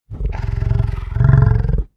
Звуки буйвола